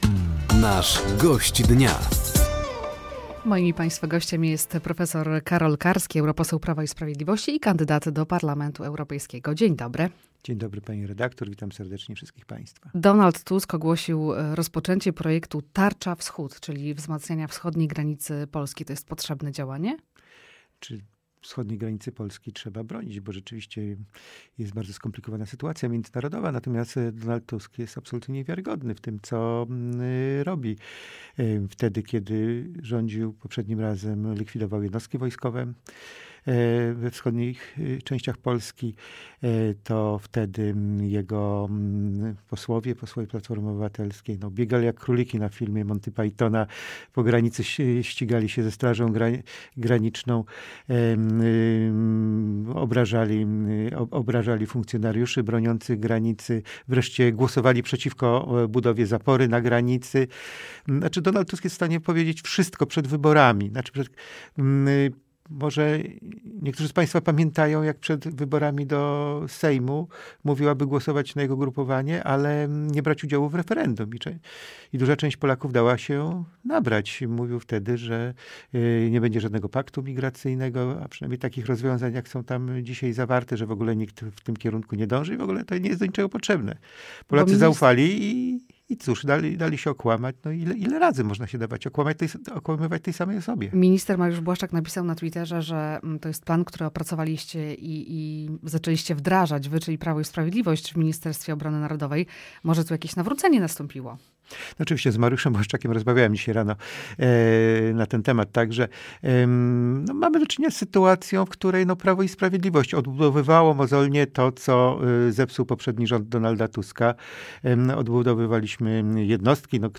Gościem Dnia Radia Nadzieja był prof. Karol Karski, europoseł PiS oraz kandydat do Parlamentu Europejskiego.